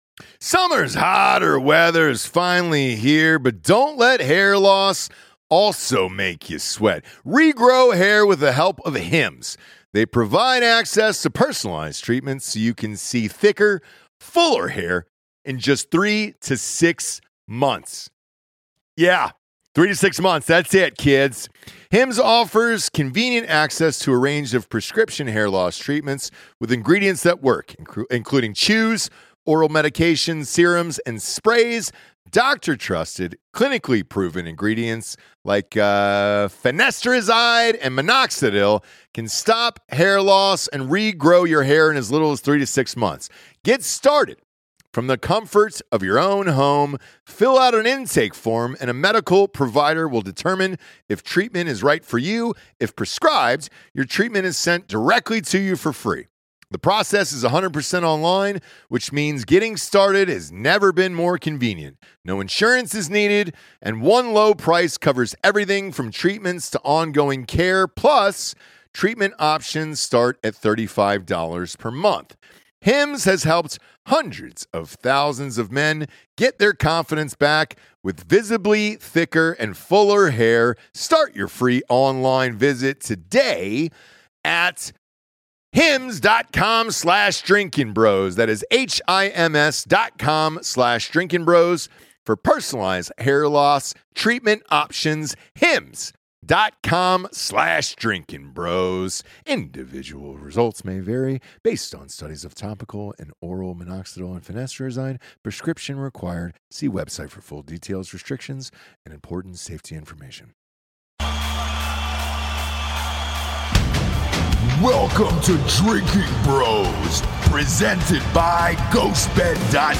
Episode 809 - Derek Chauvin Verdict LIVE